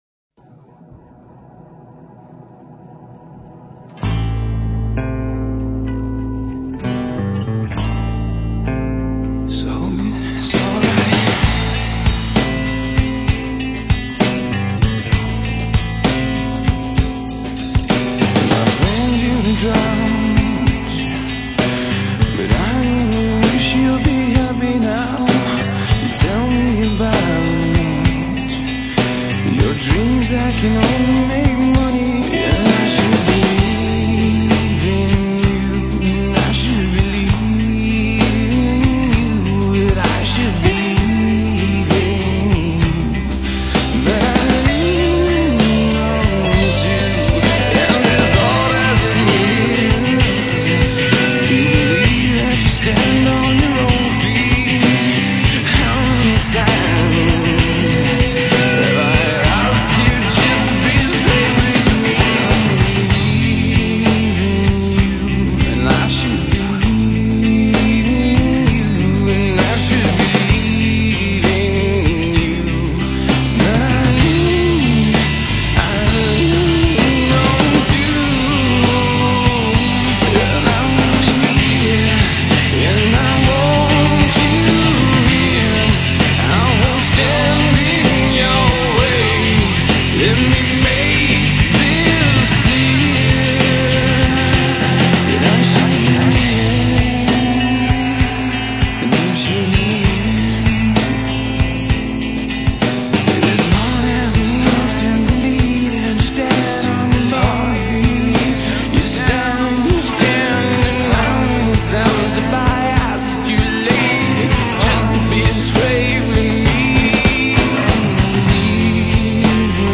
4-piece rock band from Gauteng formed in 2000.